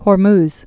(hôr-mz, hôrmŭz), Strait of also Strait of Or·muz (ôr-mz, ôrmŭz)